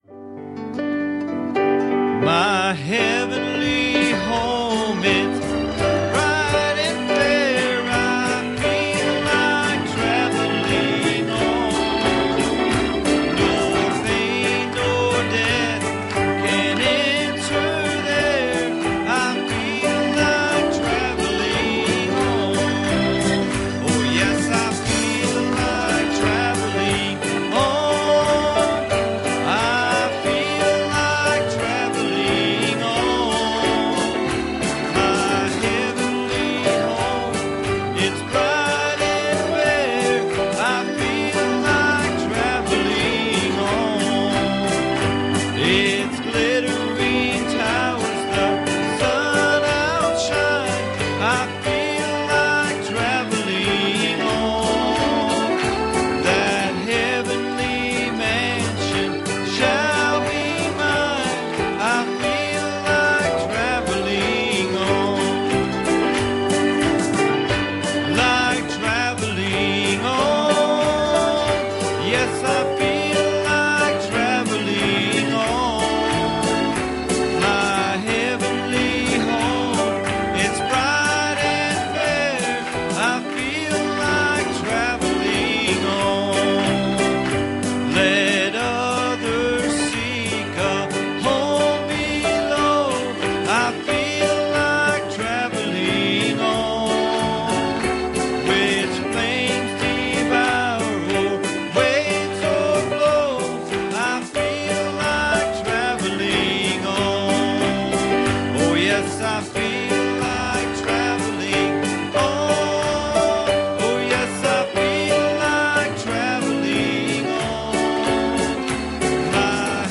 Passage: Exodus 10:21 Service Type: Wednesday Evening